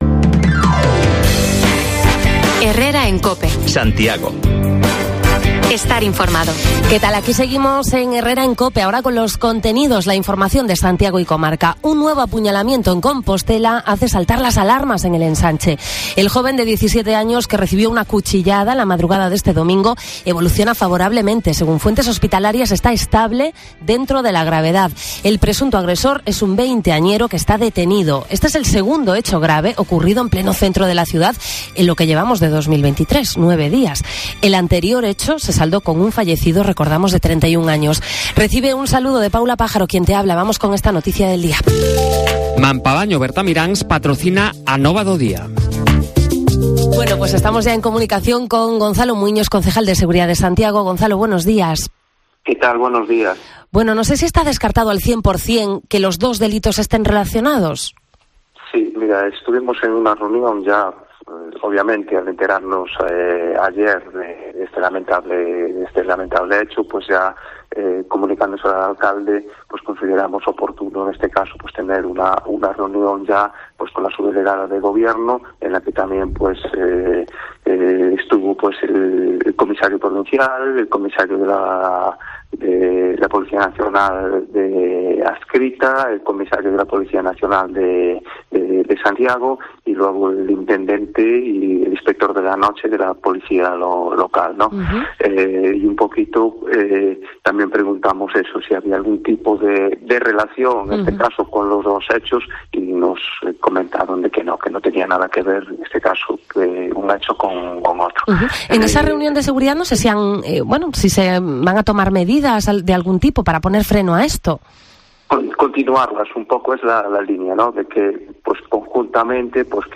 Lo hemos constatado en la calle, con vecinos y comerciantes.